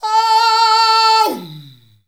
OOOOUUMMM.wav